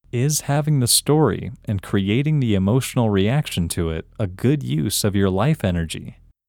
OUT – English Male 24